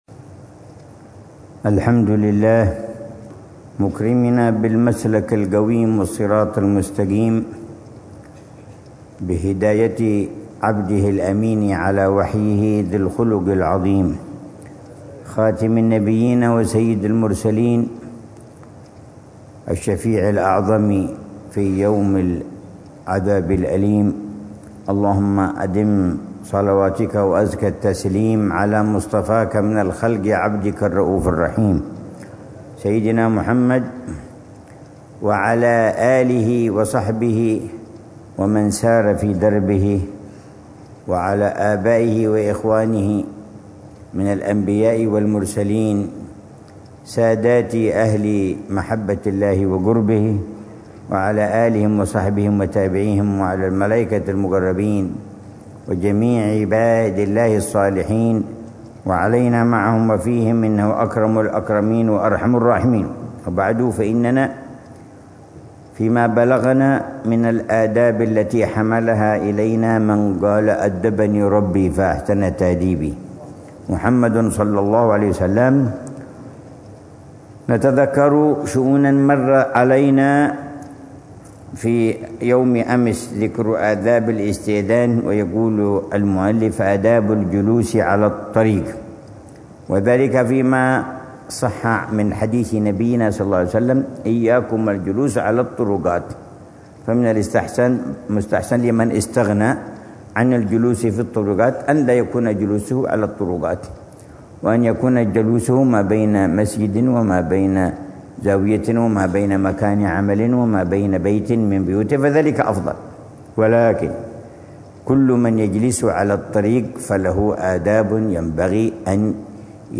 الدرس الرابع والأربعون من شرح العلامة الحبيب عمر بن حفيظ لكتاب الأدب في الدين لحجة الإسلام الإمام محمد بن محمد الغزالي، ضمن الدروس الصباحية لأ